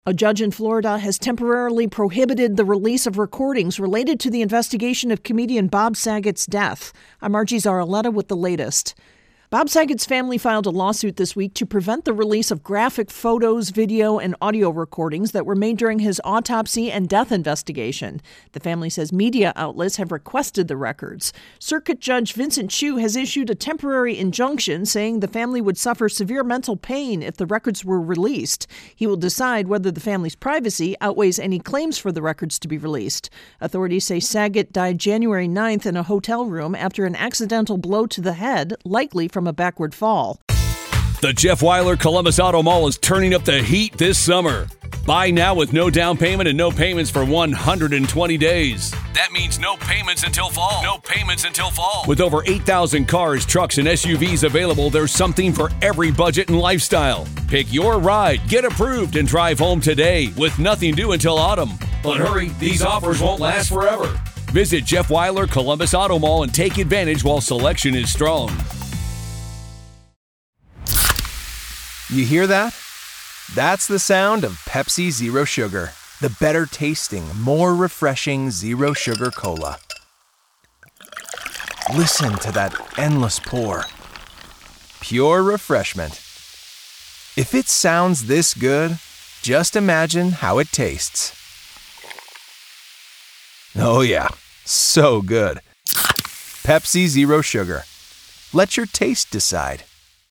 intro & voicer 2 for Bob Saget ((updates earlier voicer))